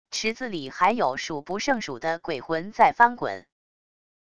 池子里还有数不胜数的鬼魂在翻滚wav音频生成系统WAV Audio Player